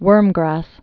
(wûrmgrăs)